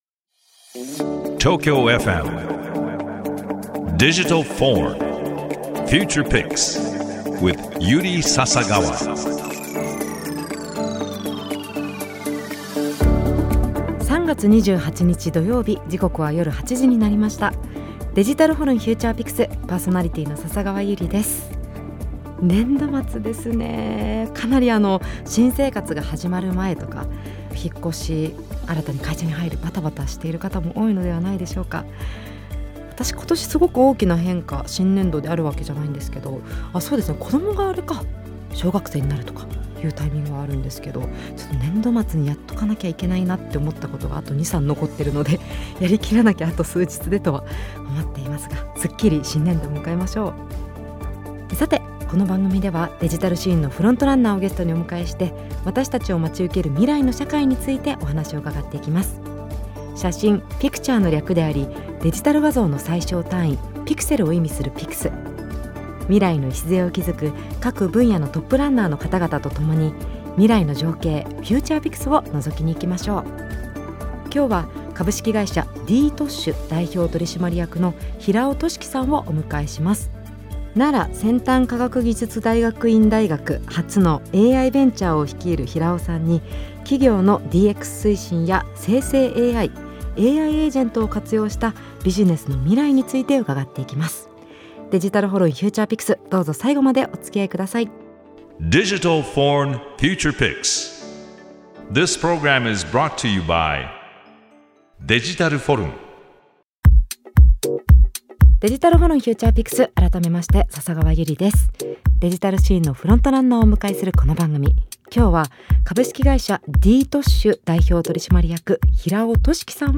デジタルシーンのフロントランナーをゲストにお迎えして、 私達を待ち受ける未来の社会についてお話を伺っていくDIGITAL VORN Future Pix。